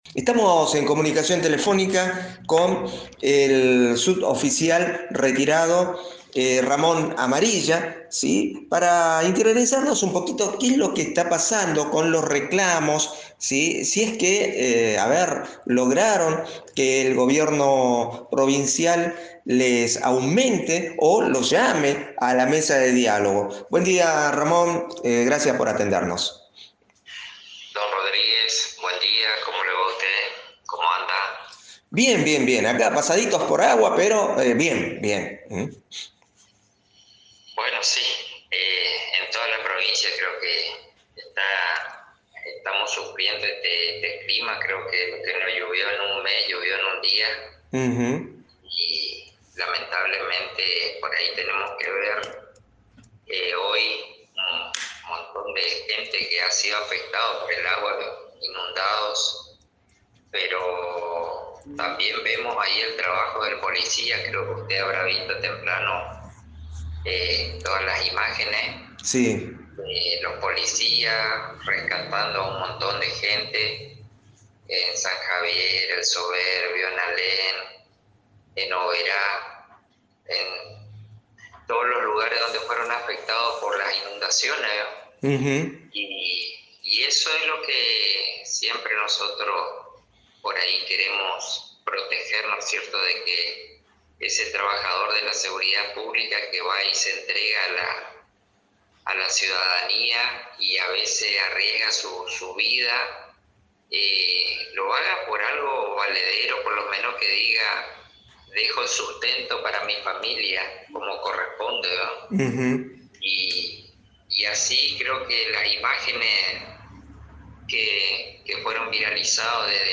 Durante una entrevista telefónica con un medio radial local